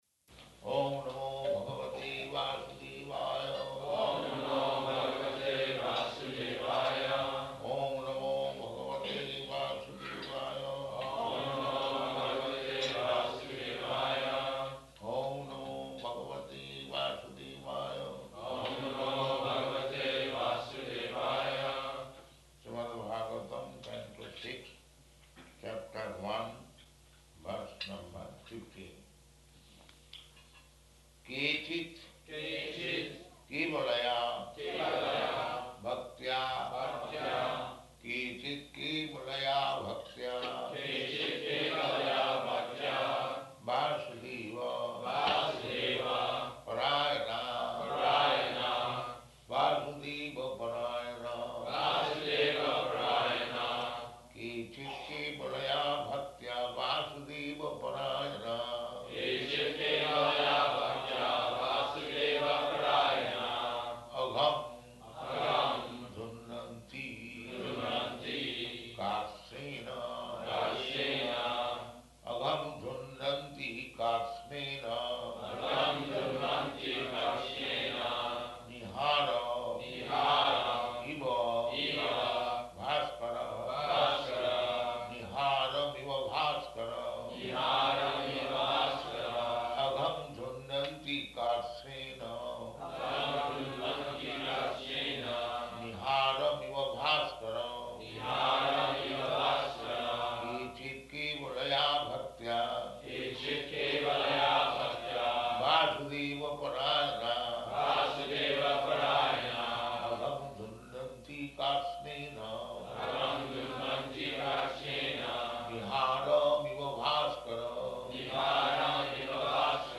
Śrīmad-Bhāgavatam 6.1.15 --:-- --:-- Type: Srimad-Bhagavatam Dated: May 15th 1976 Location: Honolulu Audio file: 760515SB.HON.mp3 Prabhupāda: Oṁ namo bhagavate vāsudevāya.
[devotees repeat]